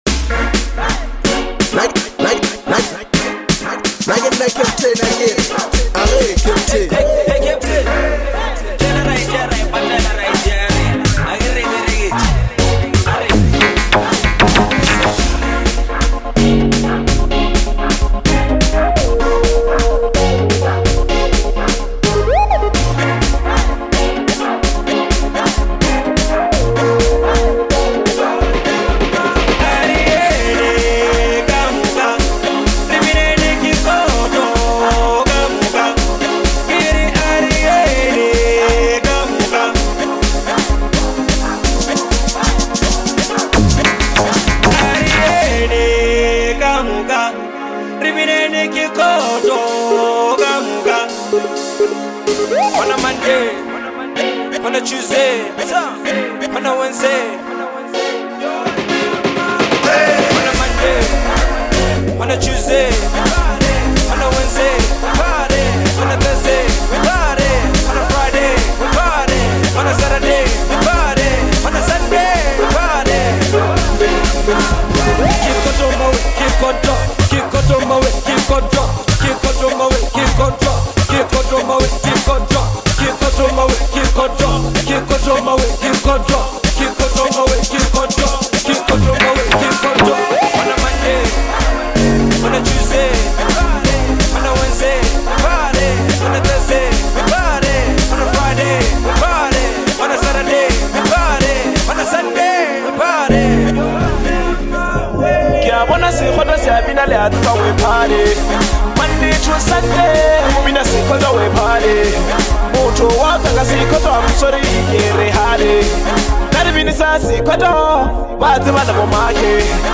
energetic and infectious track